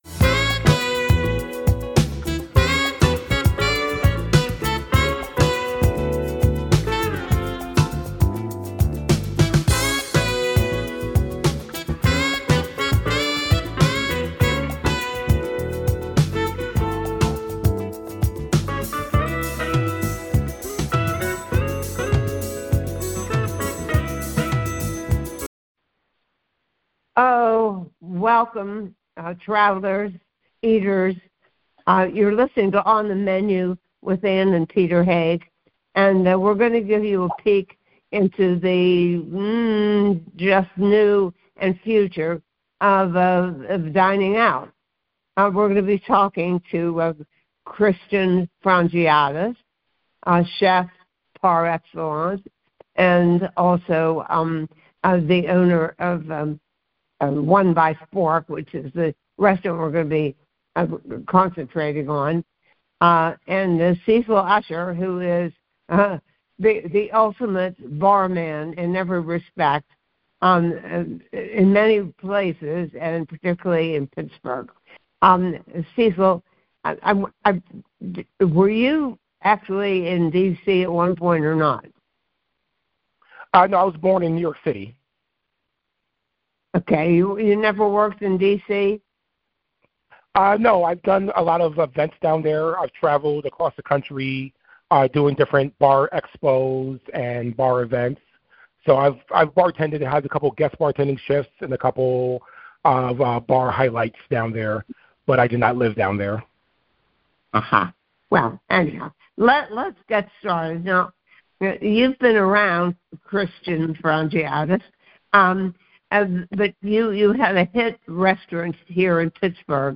Play Rate Listened List Bookmark Get this podcast via API From The Podcast A husband and wife duo, they interview chefs, restaurateurs, hoteliers, authors, winemakers, food producers, cookware and kitchen gadget makers and other culinary luminaries.